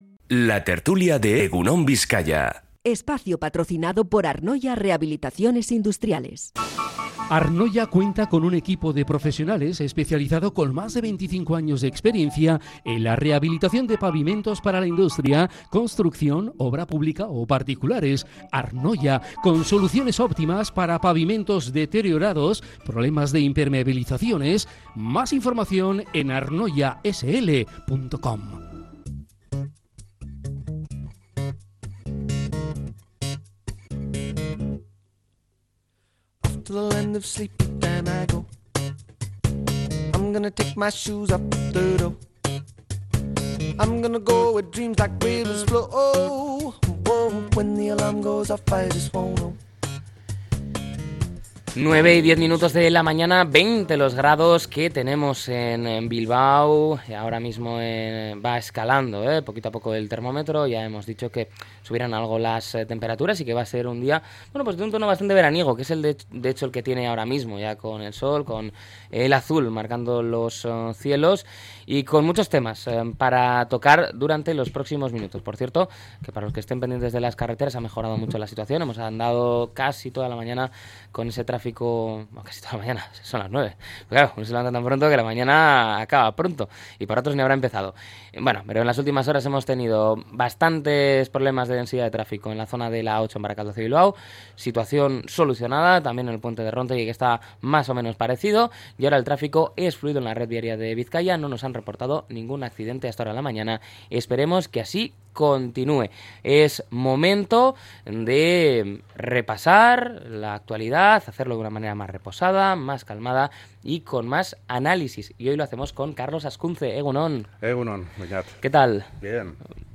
La Tertulia 22-07-25.